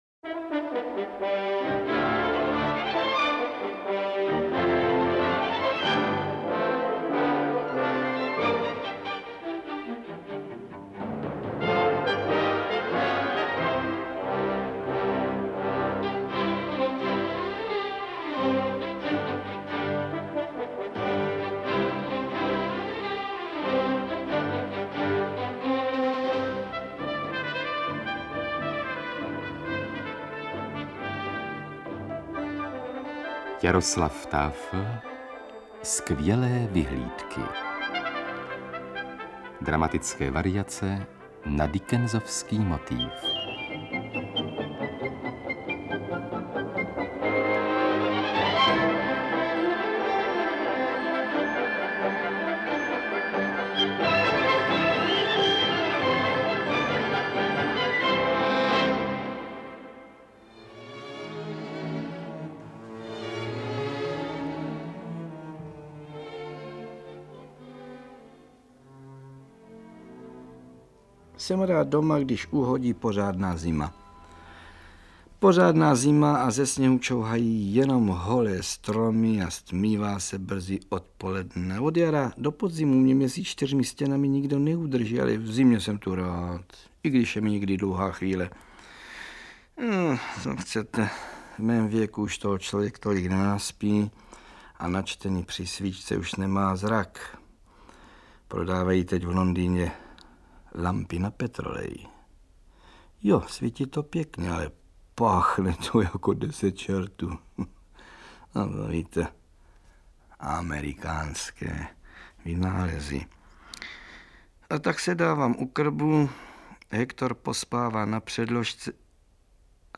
Osmidílná dramatizace slavného románu Charlese Dickense z pera Jaroslava Tafela s mimořádným hereckým výkonem Karla Högera v roli sirotka Pipa.
AudioKniha ke stažení, 8 x mp3, délka 6 hod. 8 min., velikost 673,0 MB, česky